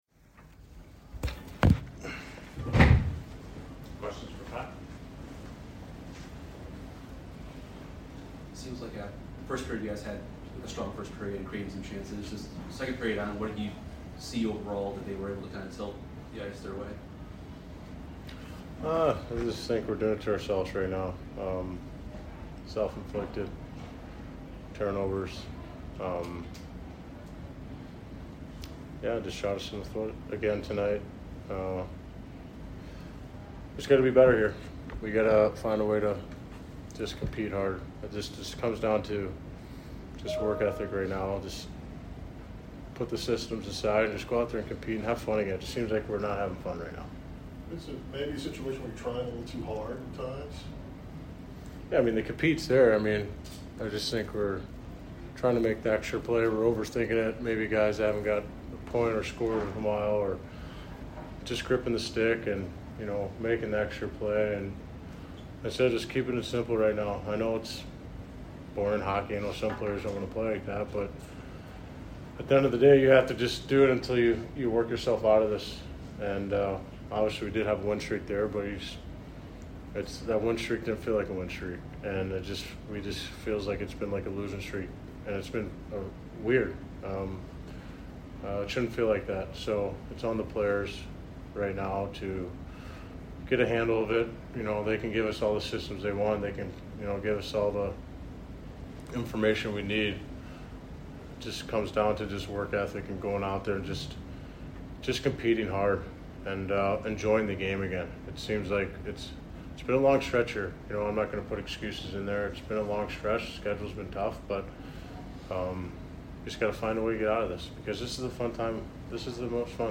Pat Maroon Post Game Vs TOR 4 - 4-2022